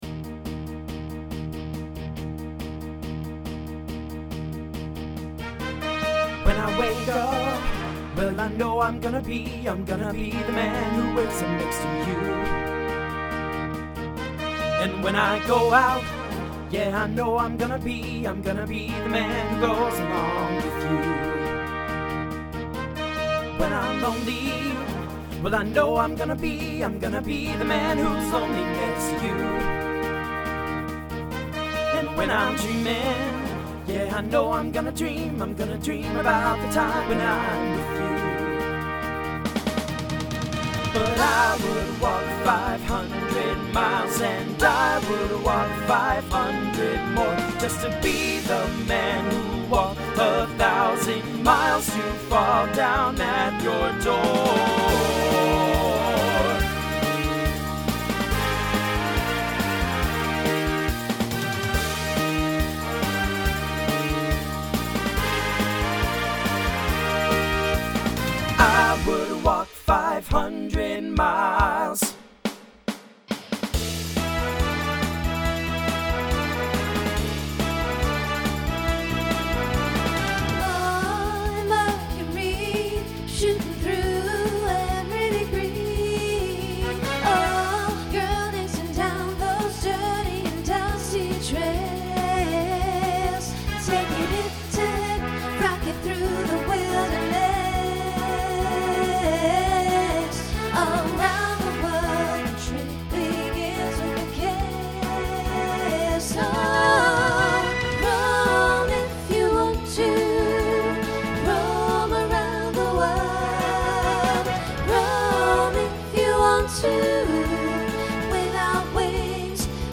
TTB/SSA
Voicing Mixed
Genre Pop/Dance